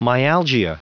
Prononciation du mot myalgia en anglais (fichier audio)
Prononciation du mot : myalgia